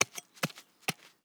SFX_Hacke_03.wav